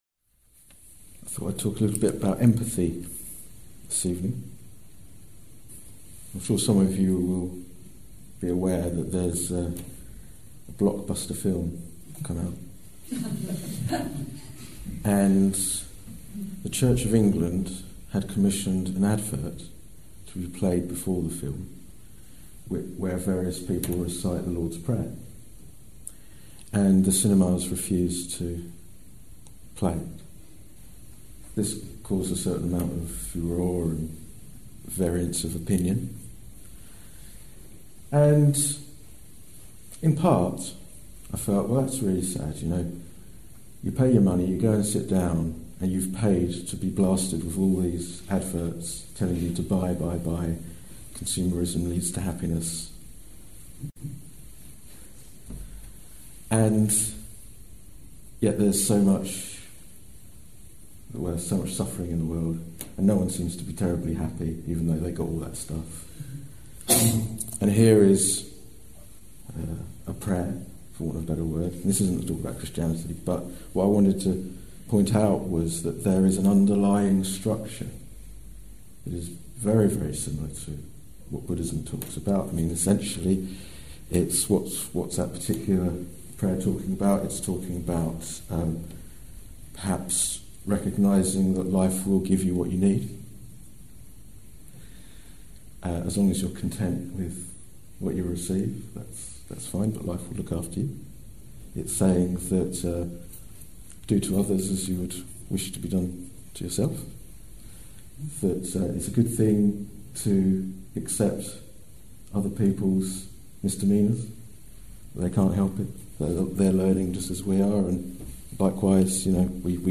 This talk was given in May 2016